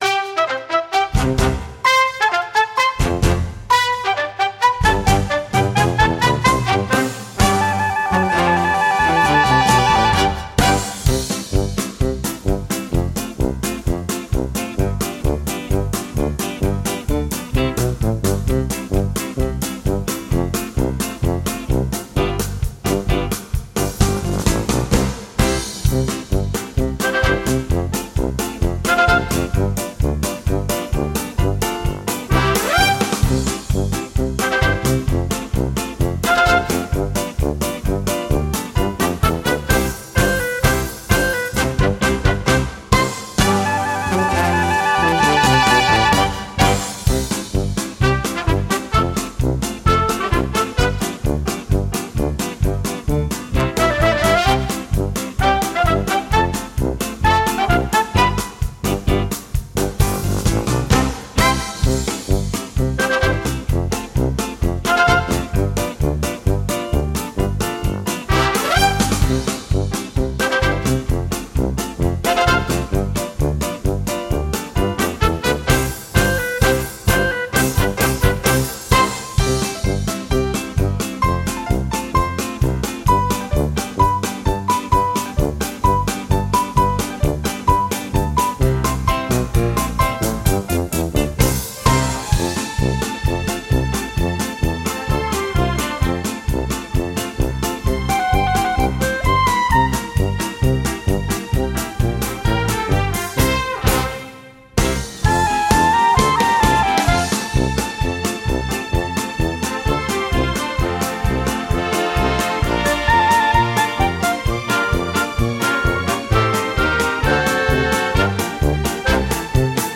Without singing: